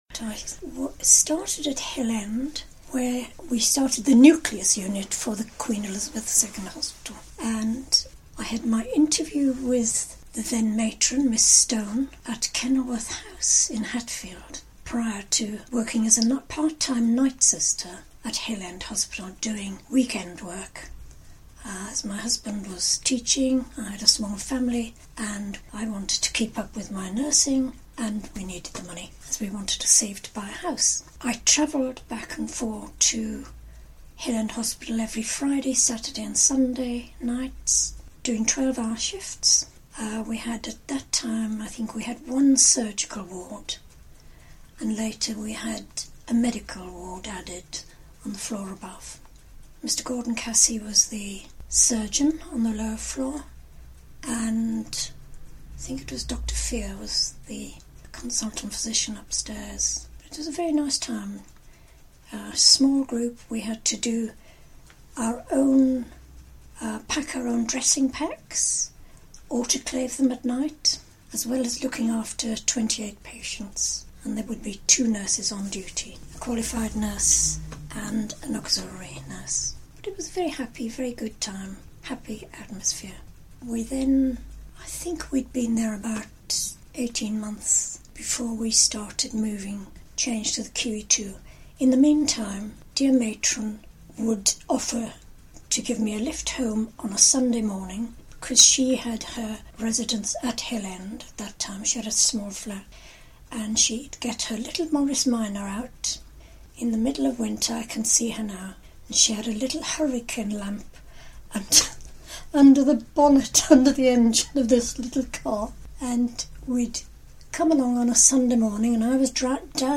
Documents from various archives including Mill Green Museum, East & North Hertfordshire NHS Trust, WGC Library, HALS, and the Welwyn Hatfield Times were consulted and oral histories recorded to capture people’s memories of the QEII.